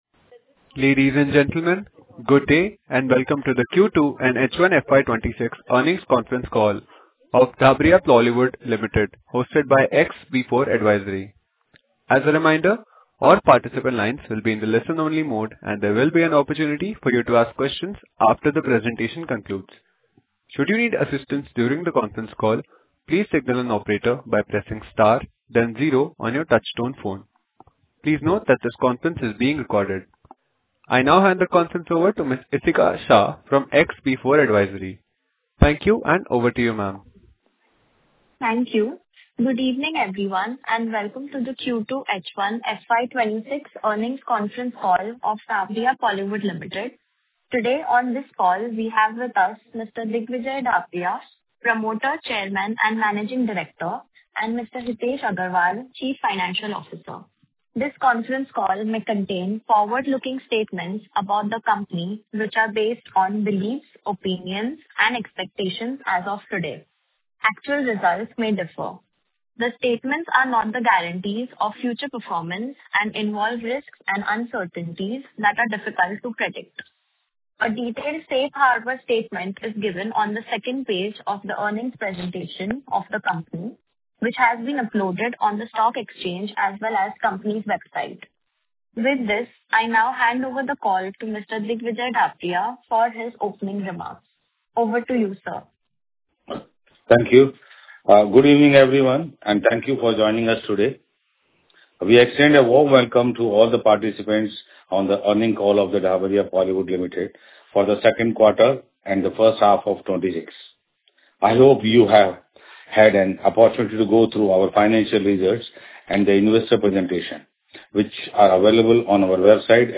Dhabriya-Polywood-Ltd-Q2-FY26-Earnings-Call-Audio-Recording.mp3